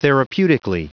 Prononciation du mot therapeutically en anglais (fichier audio)
therapeutically.wav